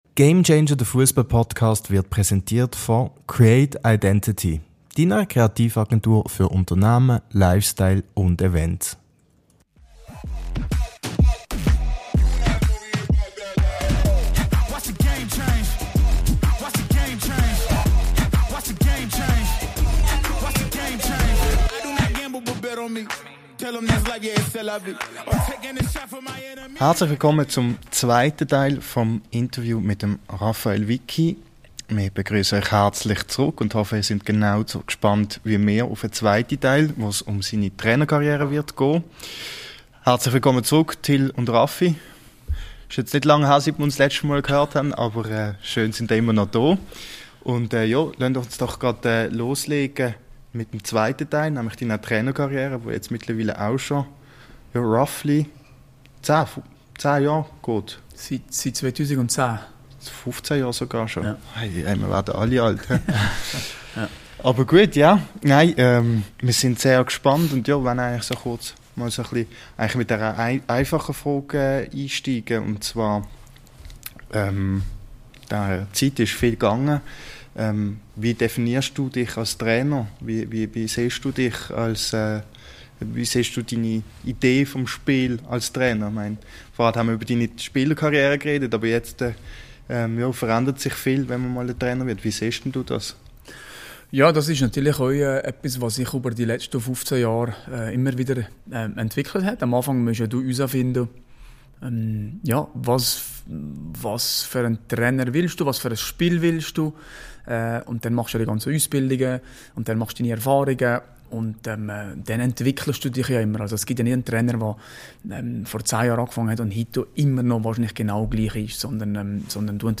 Wie es aus Wickys Sicht war, erfahrt ihr im Interview.